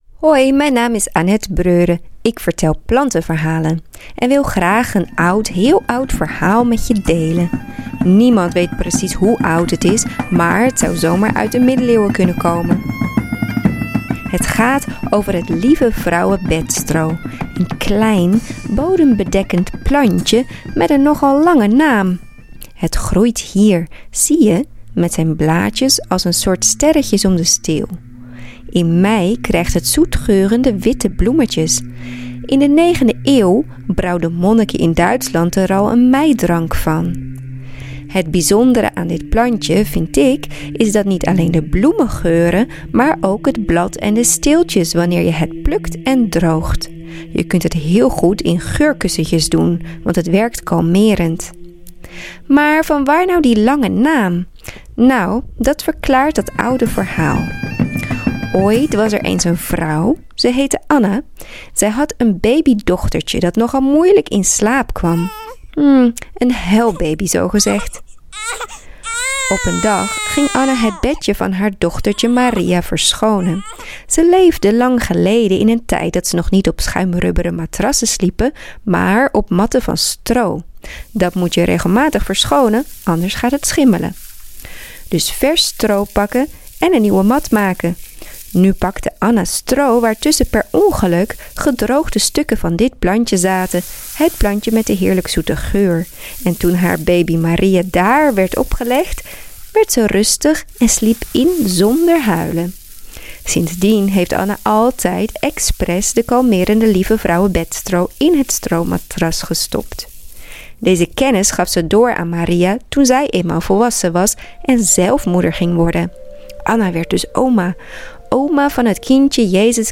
verhaal